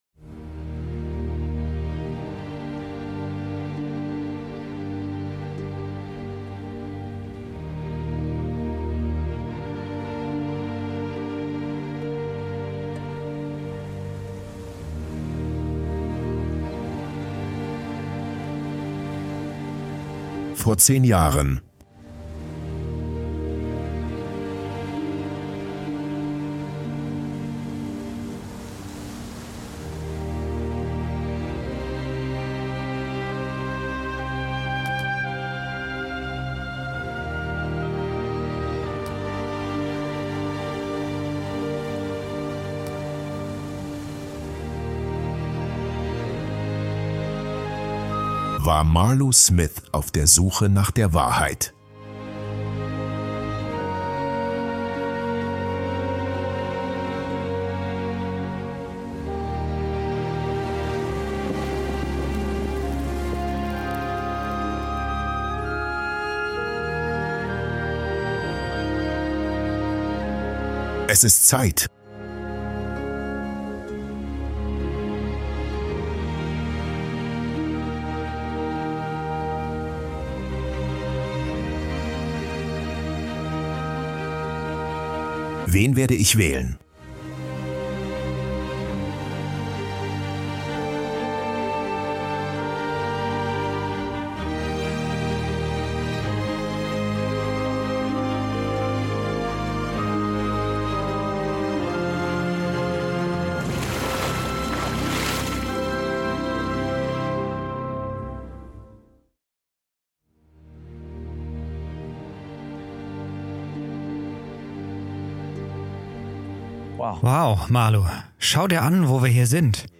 Es ist Zeit - das persönliche Zeugnis